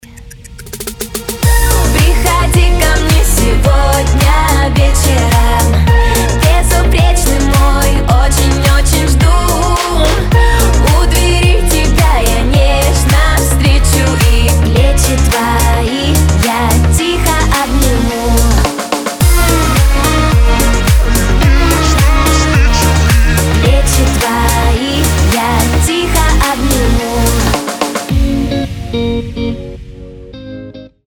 • Качество: 320, Stereo
поп